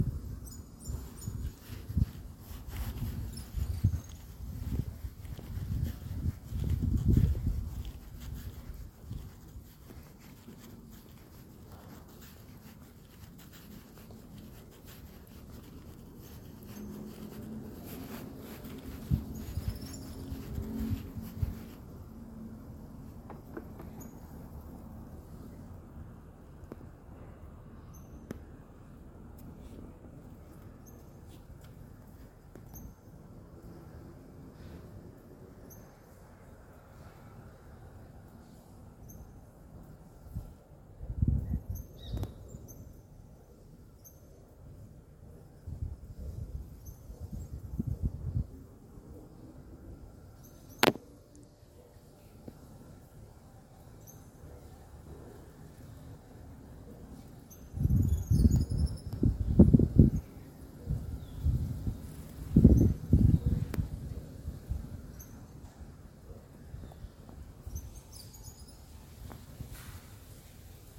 Suiriri-cavaleiro (Machetornis rixosa)
Nome em Inglês: Cattle Tyrant
Província / Departamento: Tucumán
Condição: Selvagem
Certeza: Gravado Vocal
PICABUEY.mp3